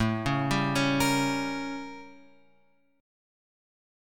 A7b9 chord {5 4 5 6 x 6} chord